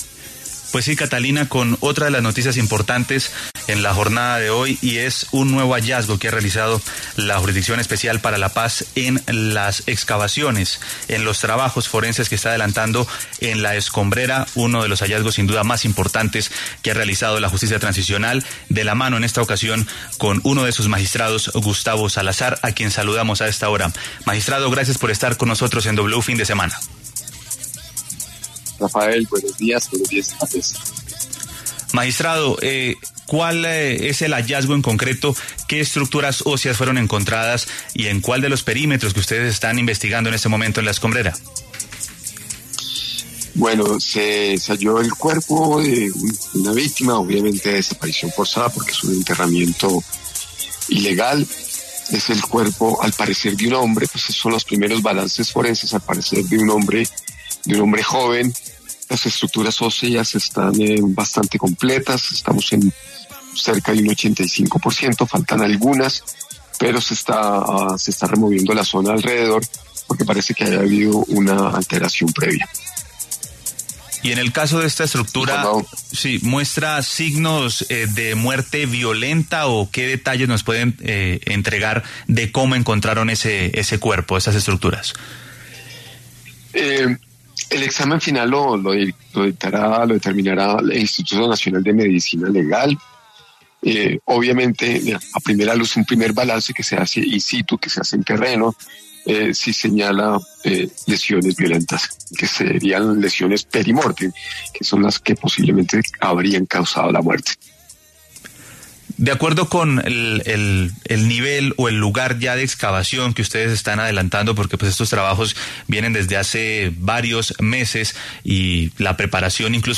Gustavo Salazar, magistrado de la JEP, se refirió en W Fin de Semana al hallazgo de nuevos restos humanos en La Escombrera de la Comuna 13 en Medellín.